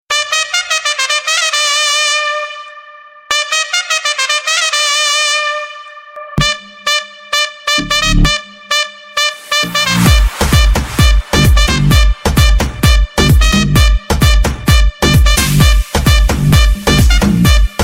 تسميات : horn